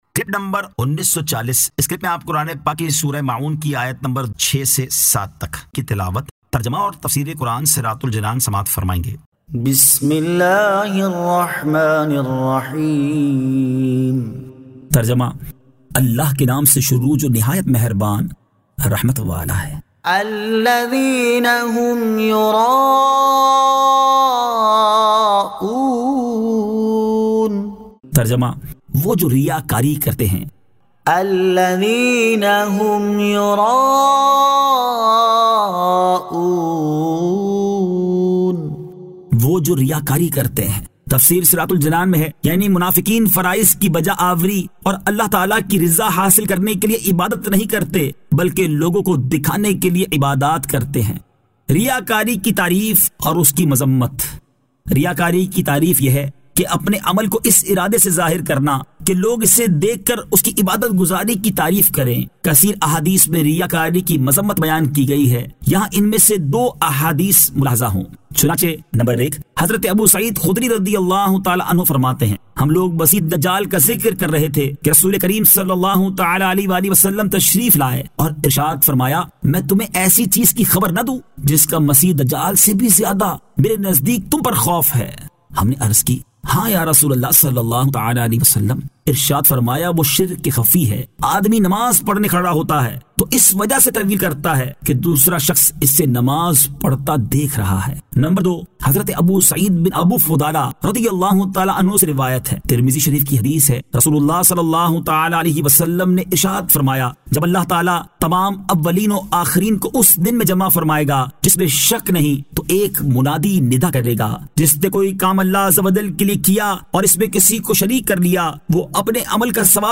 Surah Al-Ma'un 06 To 07 Tilawat , Tarjama , Tafseer